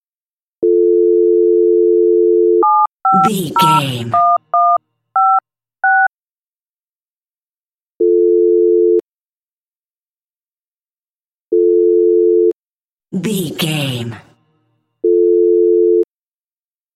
Telephone tone dial 7 numbers
Sound Effects
phone